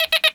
pgs/Assets/Audio/Comedy_Cartoon/beep_funny_scanning_targeting_02.wav
beep_funny_scanning_targeting_02.wav